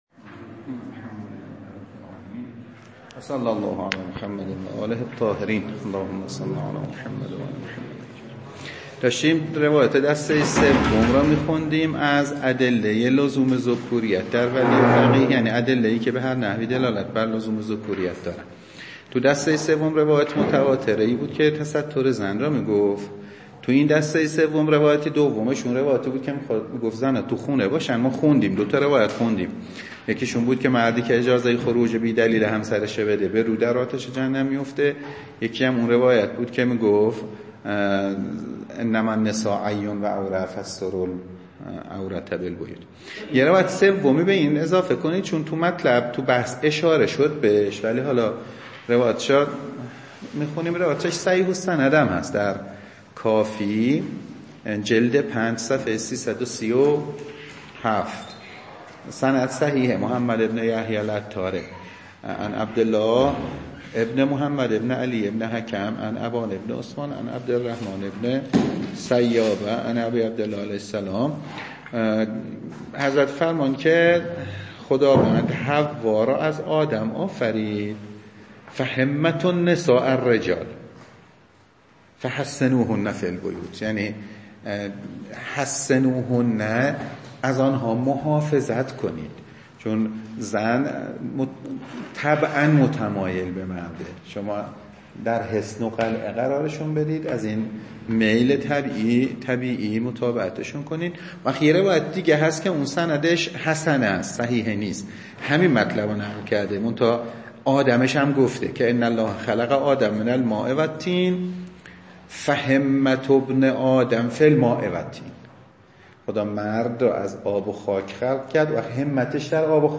درس خارج فقه